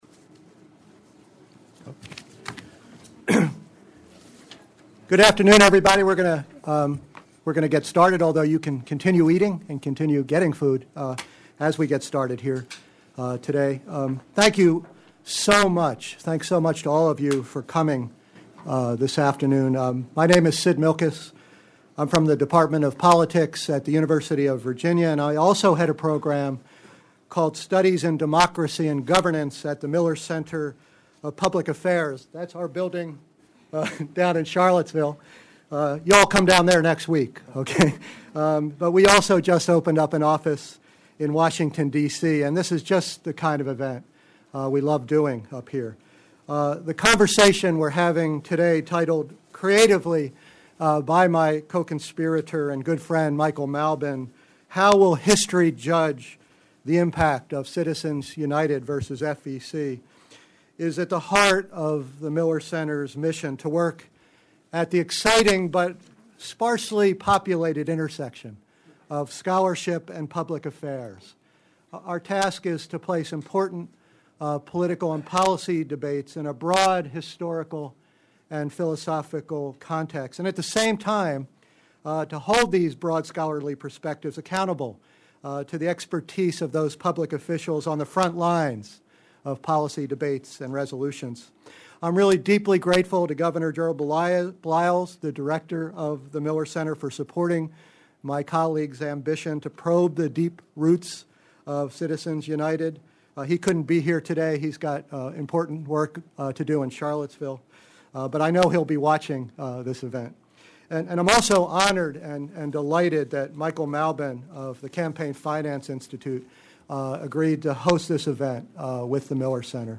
On October 18, the GAGE program co-hosted a special panel with the Campaign Finance Institute on Citizens United v. FEC in Washington, D.C.